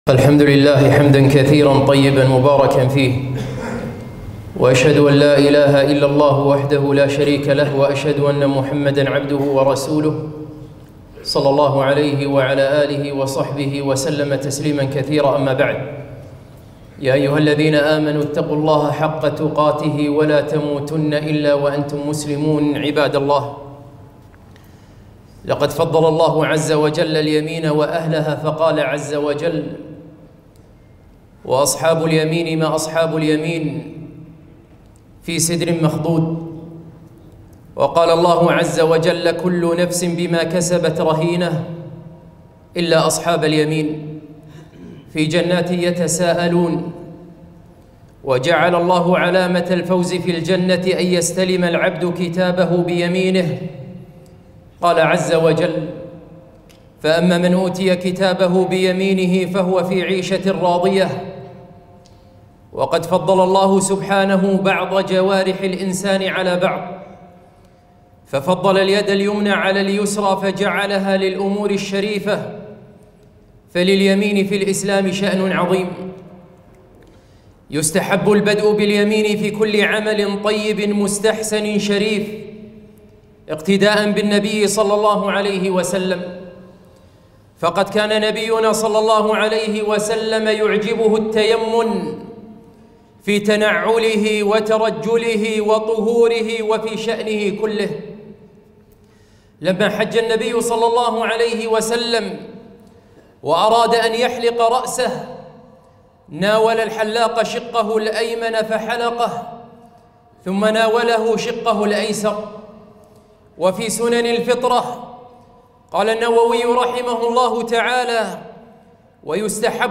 خطبة - التيامن